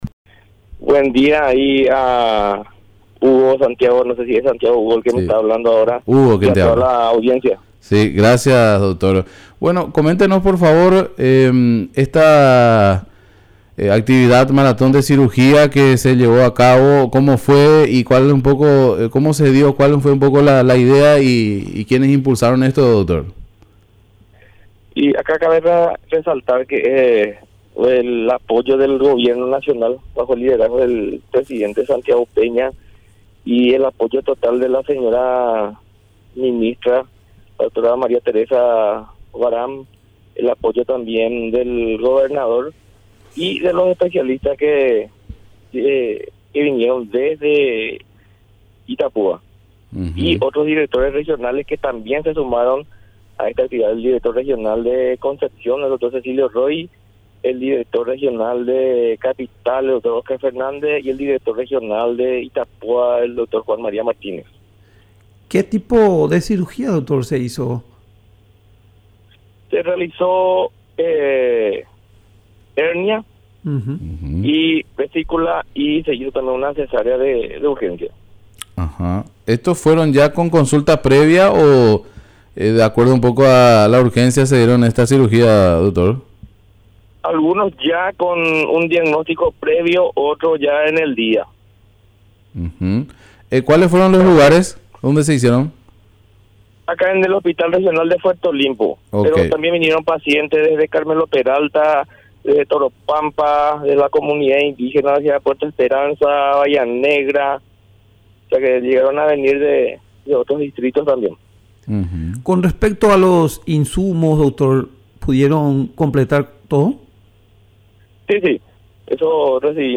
Entrevistas / Matinal 610
Entrevistado: Dr. Ariel Acuña
Estudio Central, Filadelfia, Dep. Boquerón